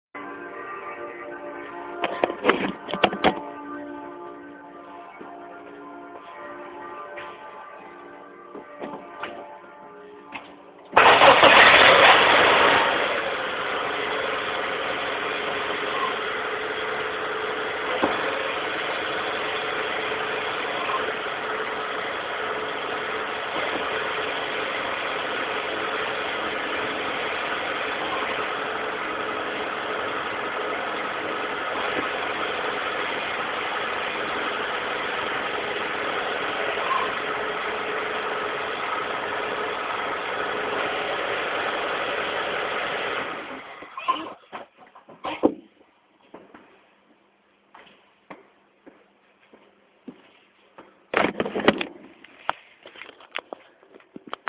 So habs aufgenommen, ist aber irgendwie schlecht gelungen und am Anfang hört man die verdammt Kirche xD.
Alles klar, hört sich ja noch ganz gut an, also bei mir ist es ein bissl lauter.